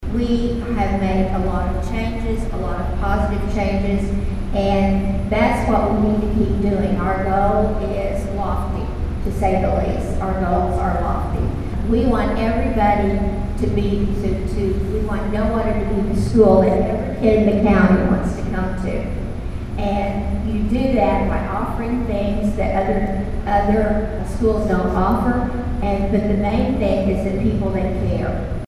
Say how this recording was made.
Two candidates for the Nowata Board of Education appeared at a forum on Monday night in the district administration building.